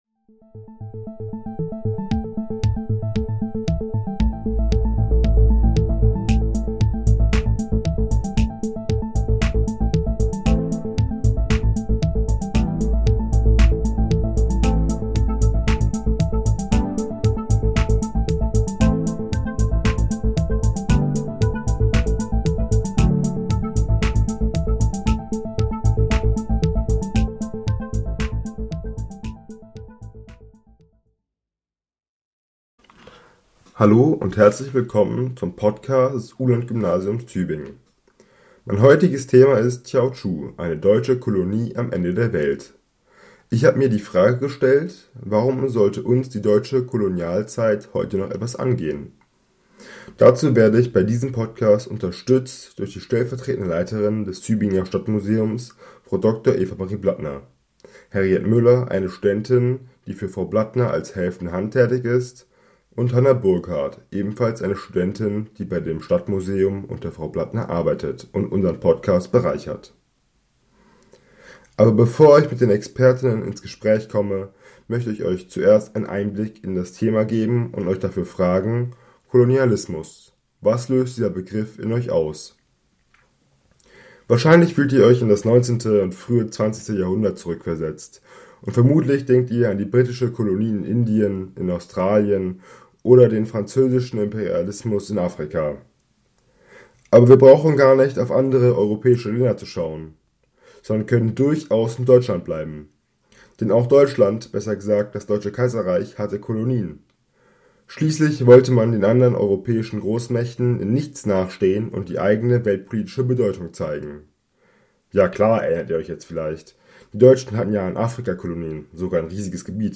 Kolonialismus ist ein wichtiger Teil der Geschichte Deutschlands, doch normalerweise denkt man im Zusammenhang mit deutschem Kolonialismus eher an Afrika. Aber in der heutigen Folge stellen wir die Geschichte des Kolonialismus des deutschen Kaiserreiches in China vor und befragen Expertinnen...